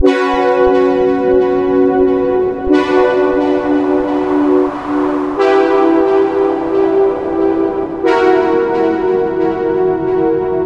平滑垫
标签： 90 bpm Chill Out Loops Pad Loops 918.79 KB wav Key : A
声道单声道